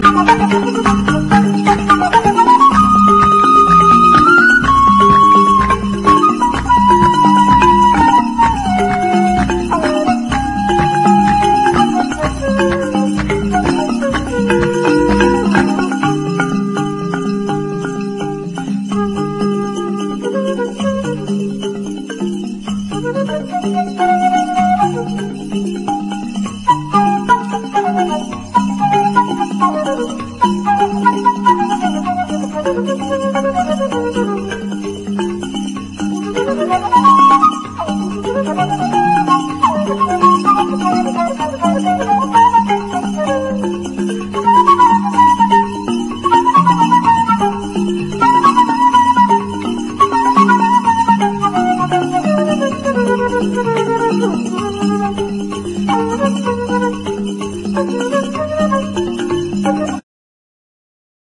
ROCKABILLY / OLDIES / 50'S
粋でワイルドなダンス・ナンバーばかりを収めた入門編にも最適の編集盤！